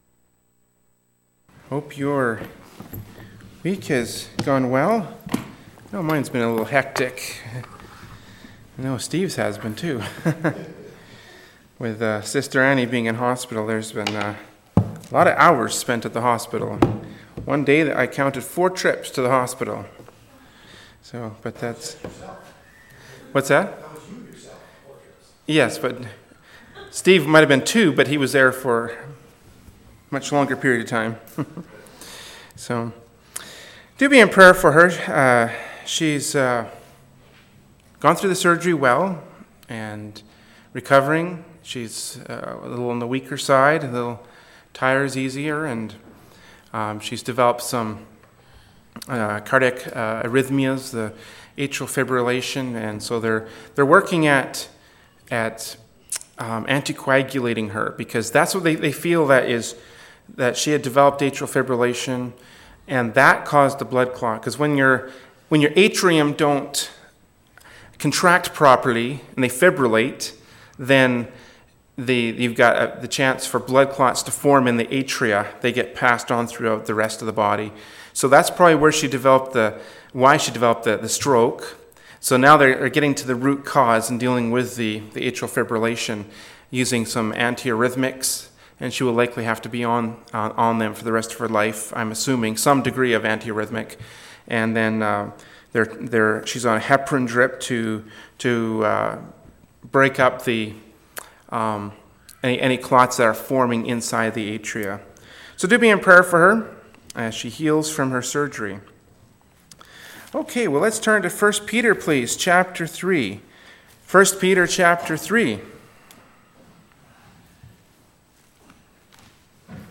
“1st Peter 3:1-7” from Wednesday Evening Service by Berean Baptist Church.